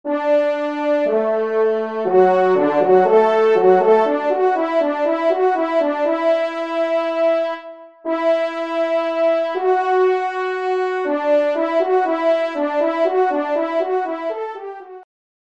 Pupitre 1° Tromp